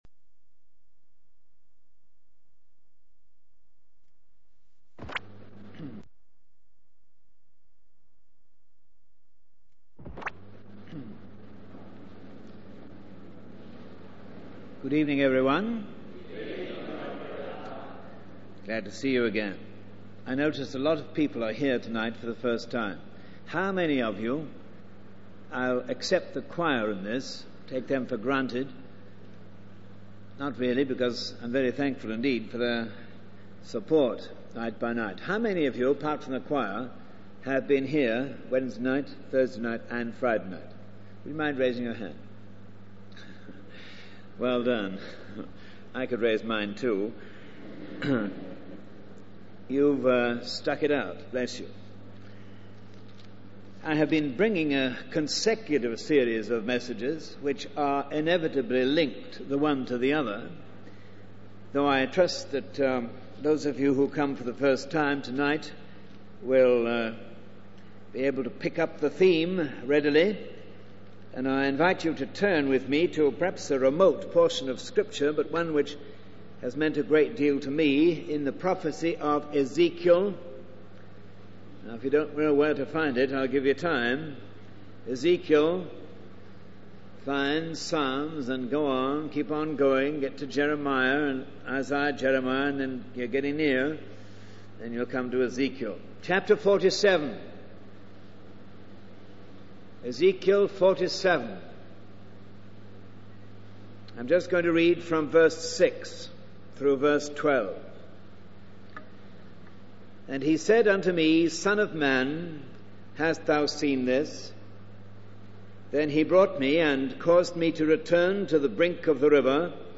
The sermon is part of a series on resources for Christian living, with a focus on the prophecy of Ezekiel. The speaker highlights the importance of fruitfulness in the Christian life, using the imagery of a river of life and trees bearing fruit. They emphasize the need for believers to move beyond mere doctrine and experience the indwelling of the Holy Spirit.